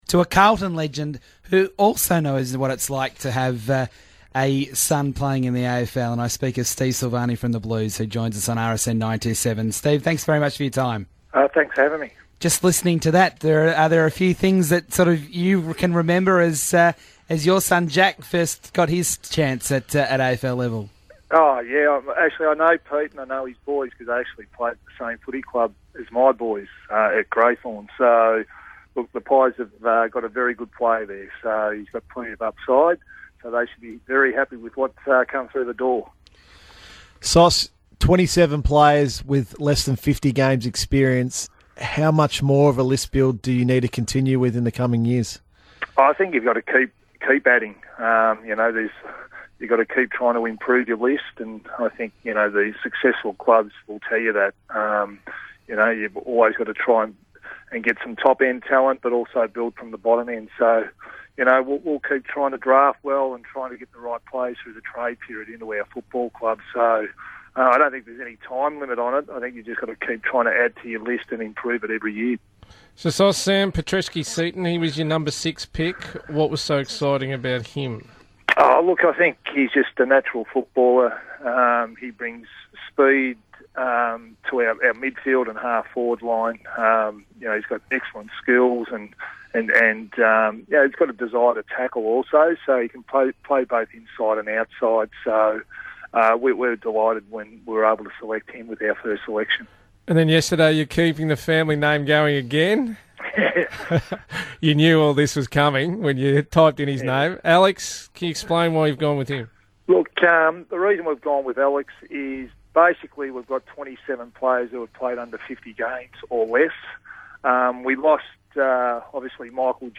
Carlton's GM of List Management and Strategy, Stephen Silvagni, chats to RSN breakfast about the Blues' trade and draft period, as the list for 2017 is finalised.